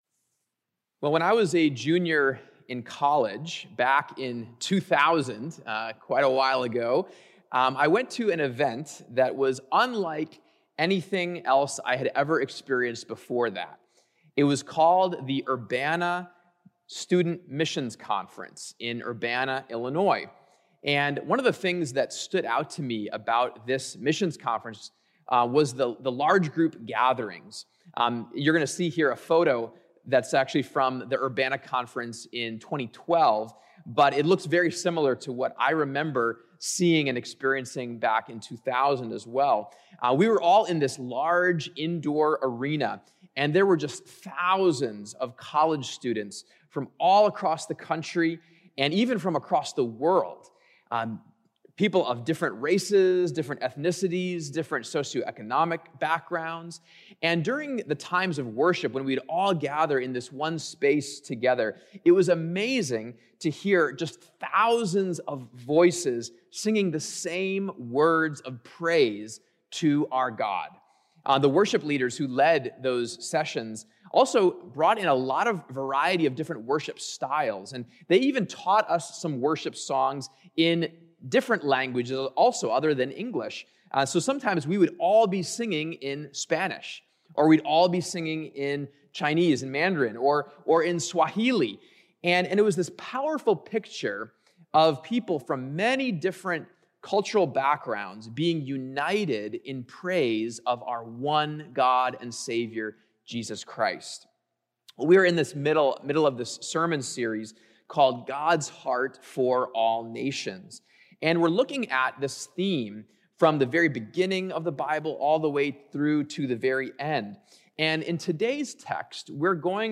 59th Street Church Sermons • God's Heart For All Nations: The Vision // February 7, 2021 • Listen on Fountain